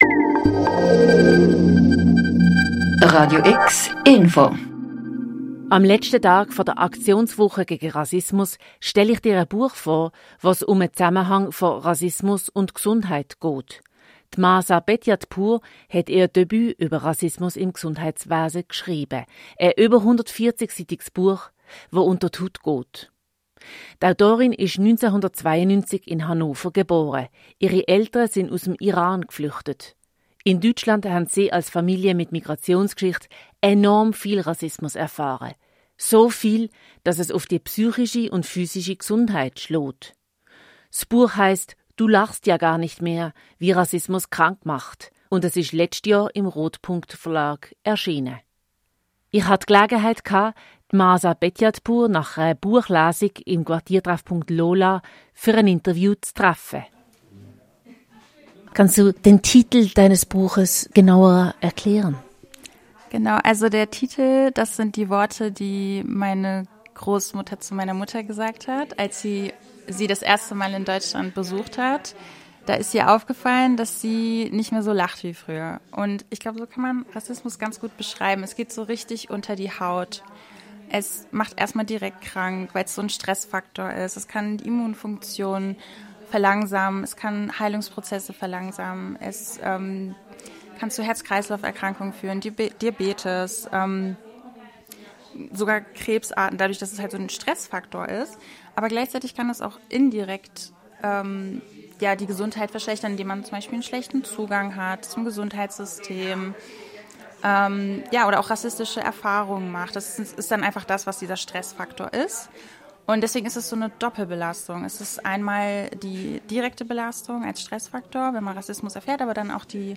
Buchtipp und Gespräch